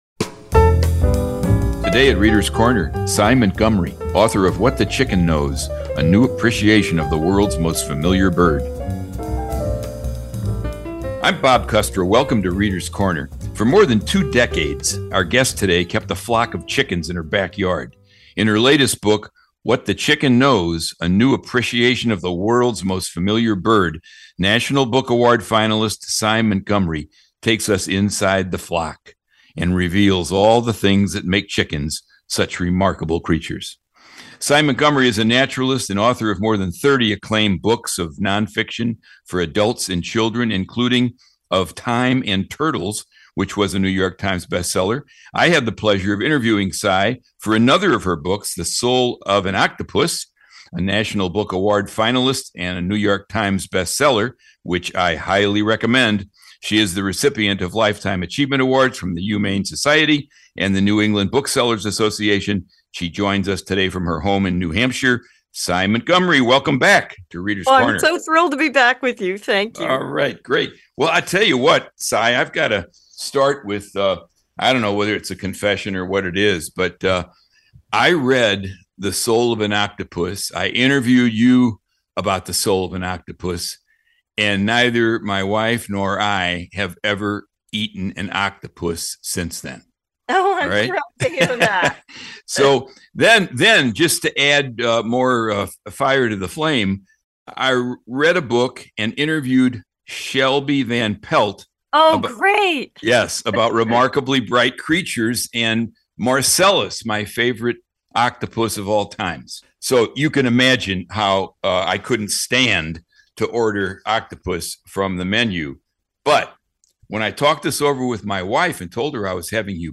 An interview with Sy Montgomery, author of What the Chicken Knows. Part science writing, part personal narrative, the book explores the surprising traits and unique personalities of the world’s most recognizable bird.